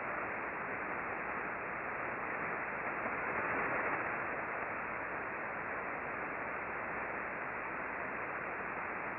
We used the Icom R-75 HF Receiver tuned to 20.408 MHz (LSB).
Click here for a recording of the bursting at 1035